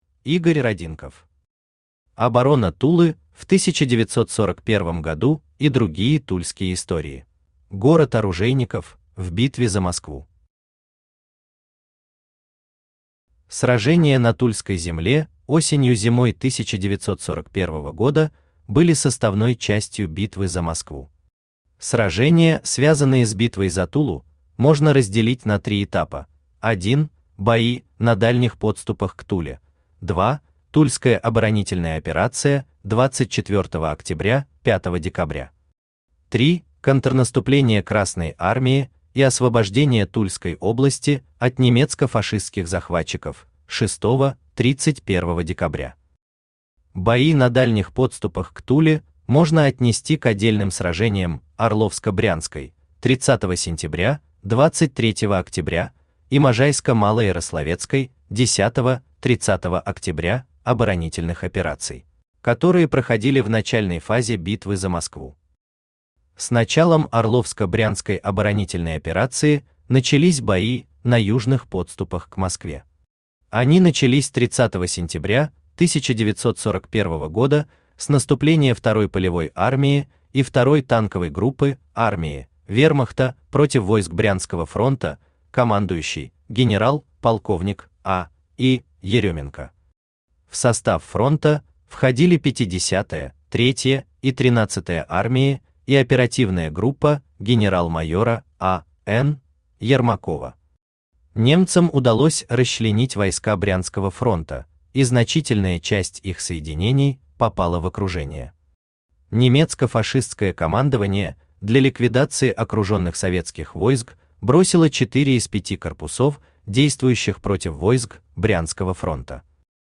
Аудиокнига Оборона Тулы в 1941 г. и другие тульские истории | Библиотека аудиокниг
Aудиокнига Оборона Тулы в 1941 г. и другие тульские истории Автор Игорь Аркадьевич Родинков Читает аудиокнигу Авточтец ЛитРес.